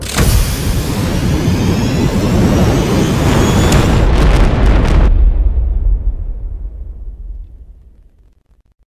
launch.wav